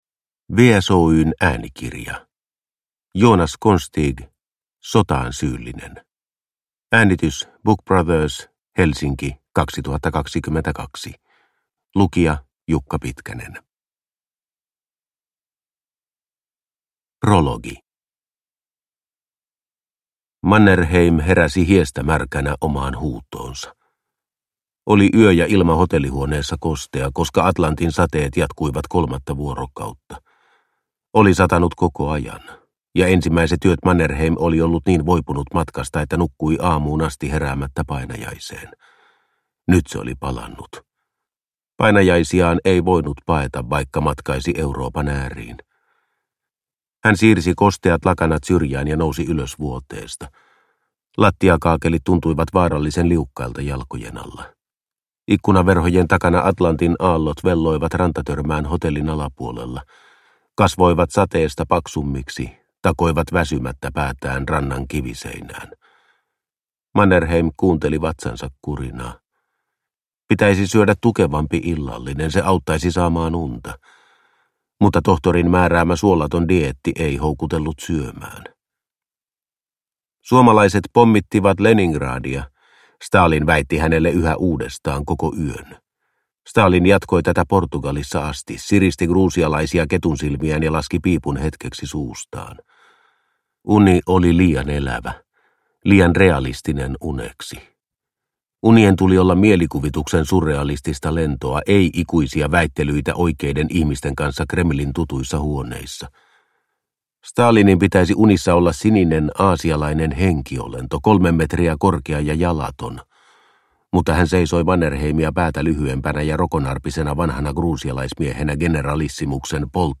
Sotaan syyllinen – Ljudbok – Laddas ner